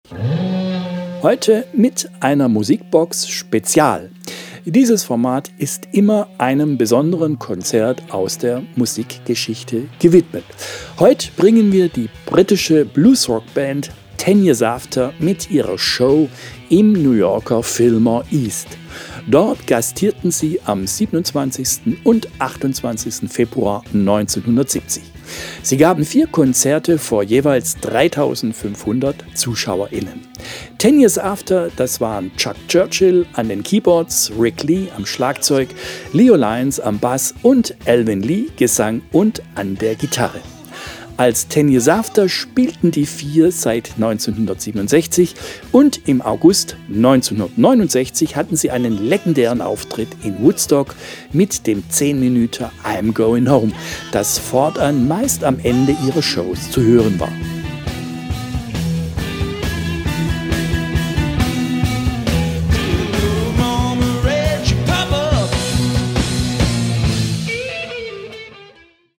Unser Format Musikbox Spezial ist immer einem besonderen Konzert aus der Musikgeschichte gewidmet. Heute bringen wir die britische Bluesrockband Ten Years After mit ihrer denkwürdigen Show im New Yorker Fillmore East.
Die Umstände für die Aufzeichnung waren schwierig.